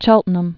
(chĕltnəm, chĕltən-əm)